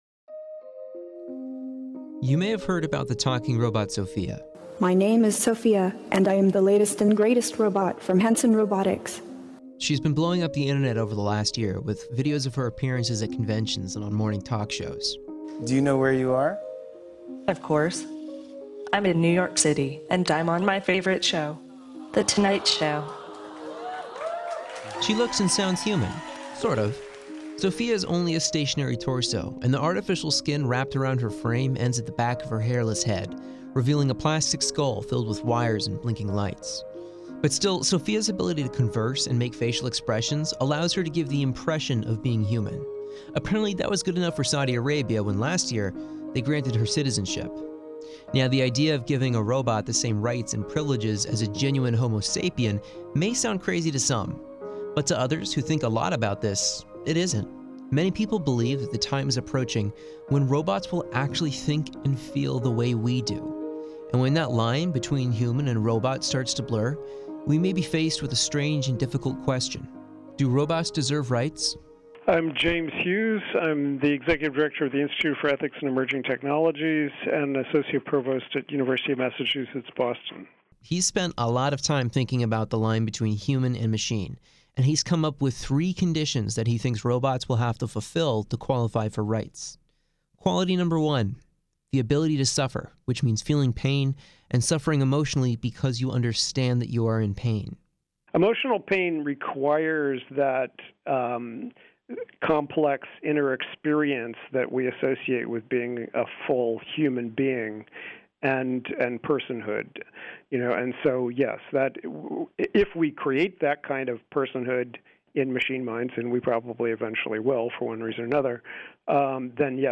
This story explores through expert interviews if robots could one day be advanced enough to deserve equivalent rights to humans.